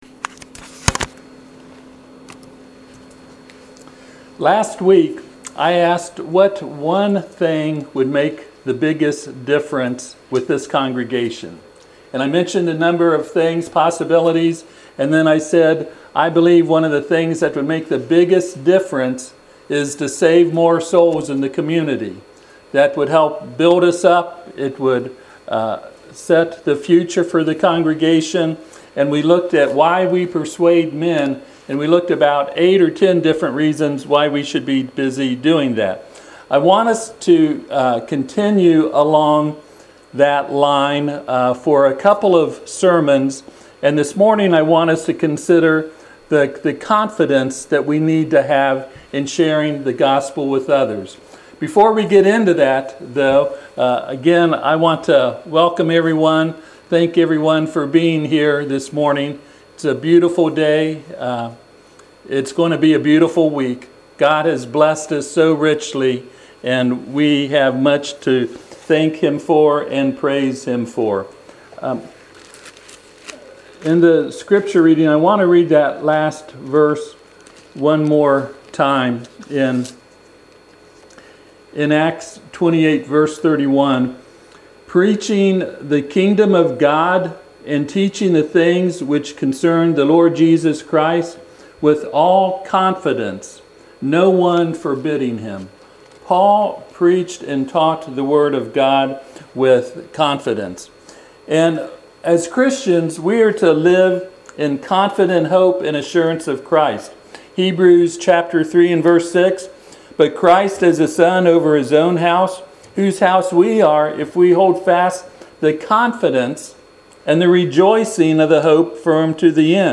Passage: Acts 28:30-31 Service Type: Sunday AM Topics